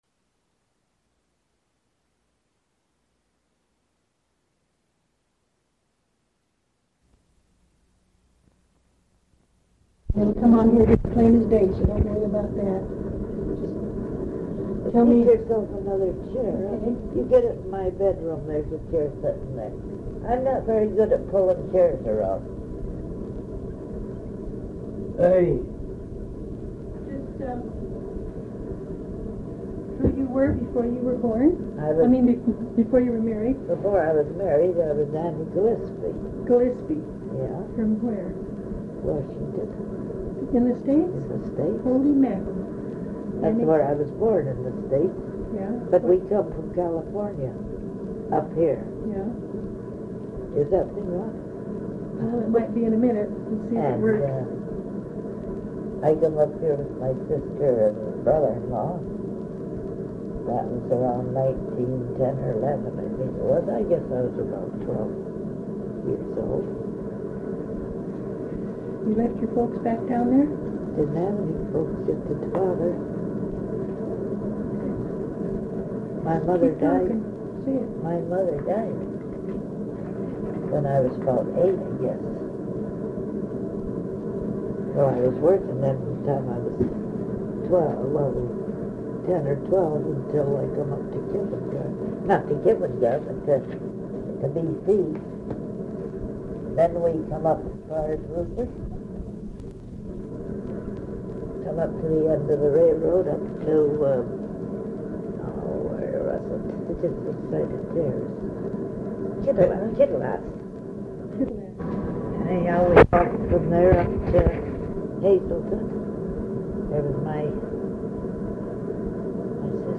Interview as part of the Hearing Hazelton History project, managed by the Hazelton Area Historical Association.,